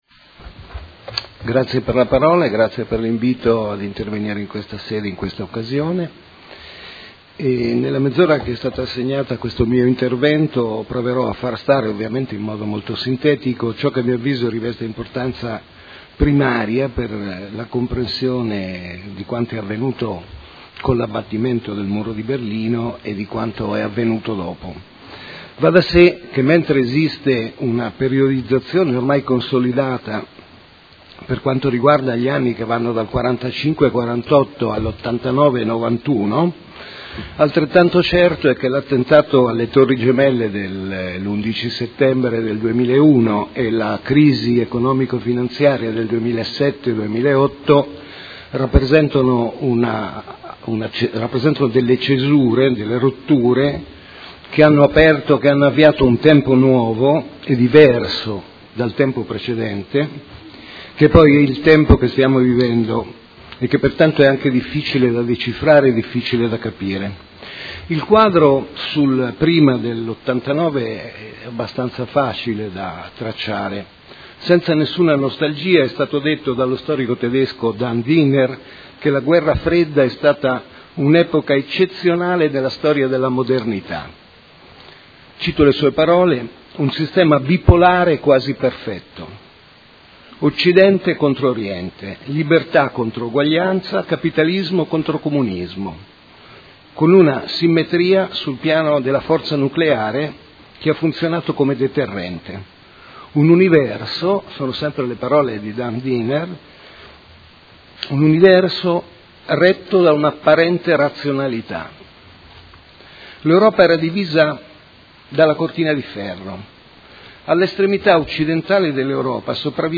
Seduta del 14/11/2019.